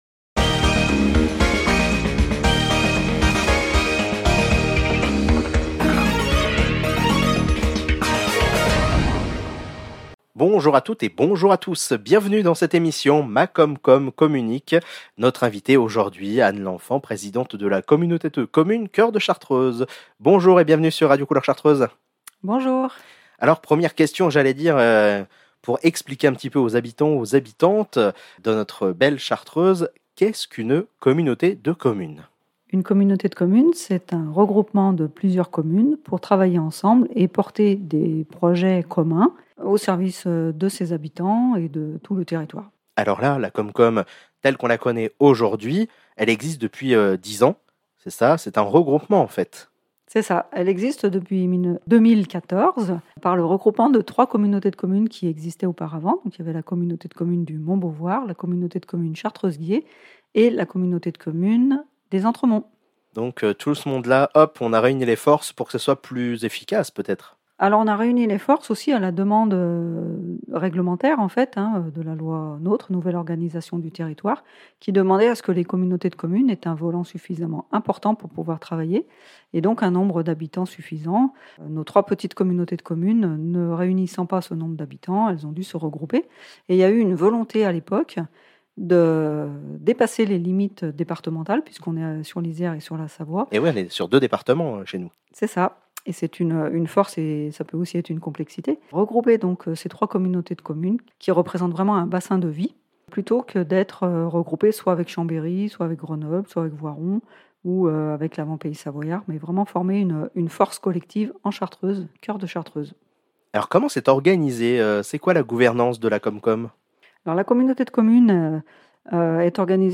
Emission de présentation de la communauté de commune coeur de chartreuse par la Présidente Anne LENFANT – Radio Couleur Chartreuse
ITW Anne Lenfant pdte comcom PAD.mp3